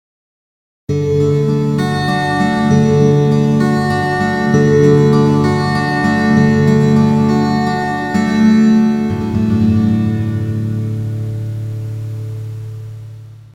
Orientační zvukové ukázky dalších efektů
Reverb
reverb.mp3